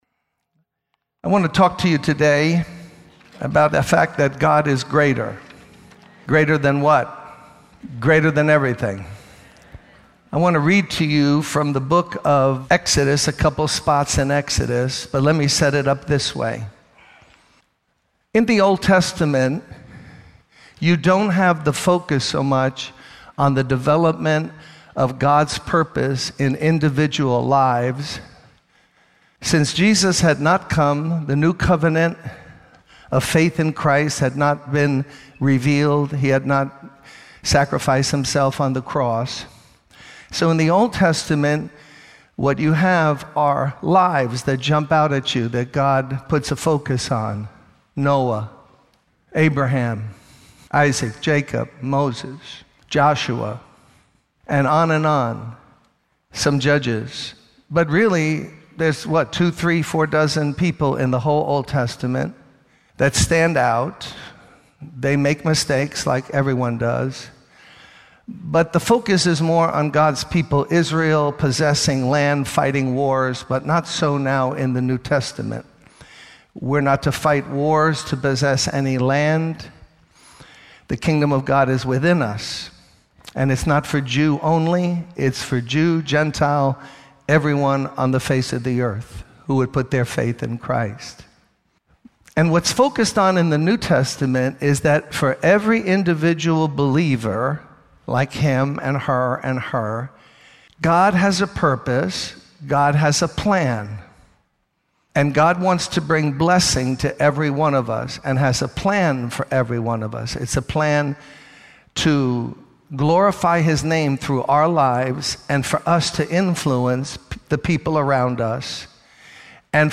In this sermon, the speaker discusses the negative things people say and the obstacles we all face in life. He uses the example of Moses and how he faced criticism and doubt from the people, but God vindicated him by performing miracles through him. The speaker encourages the audience to not quit in the face of challenges, but to believe in God's power.